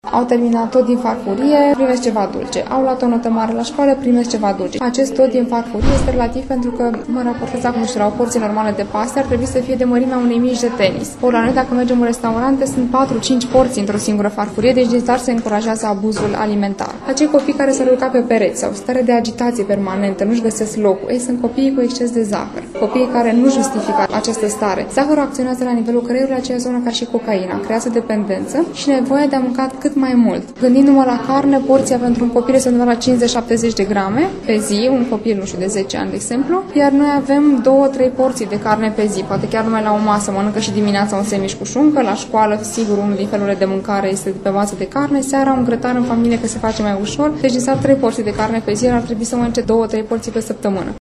spune medicul nutriționist